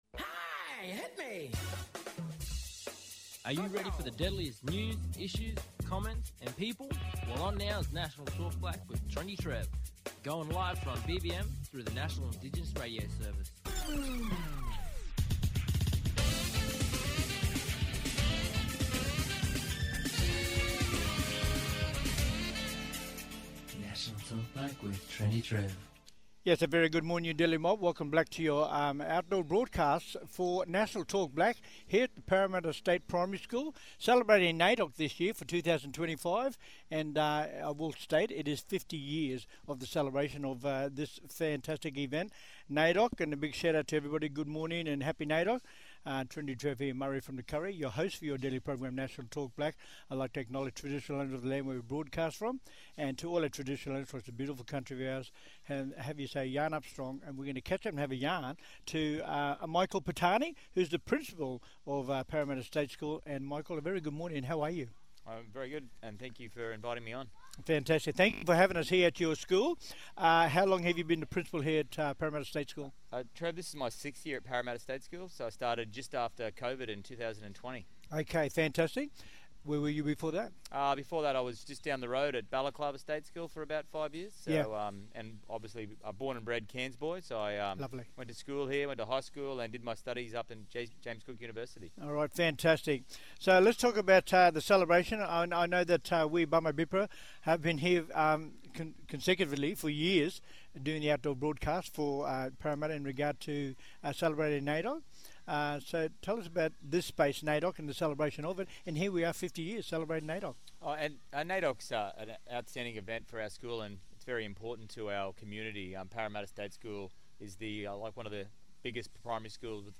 On todays National Talk Black via NIRS – National Indigenous Radio Service we have: